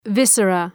Προφορά
{‘vısərə}